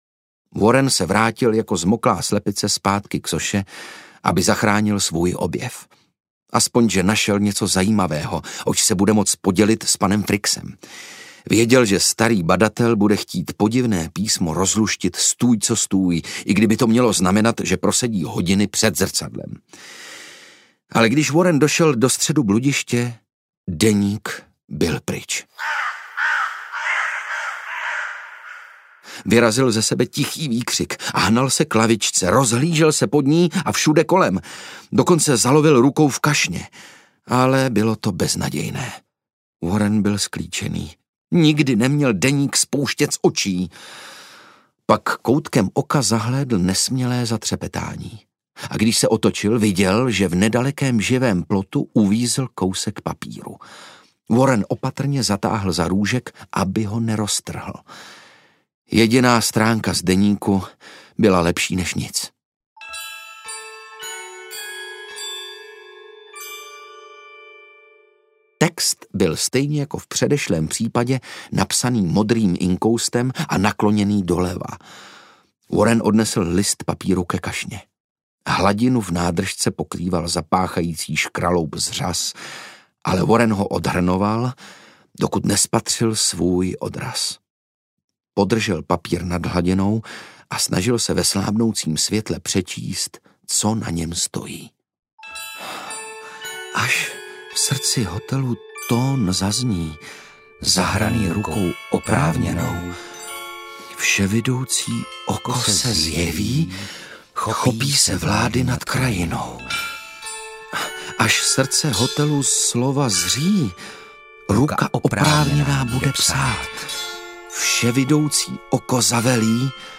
Warren XIII. a Vševidoucí oko audiokniha
Ukázka z knihy
• InterpretOndřej Brousek, Otakar Brousek ml.